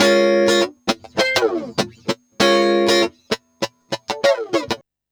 100FUNKY03-R.wav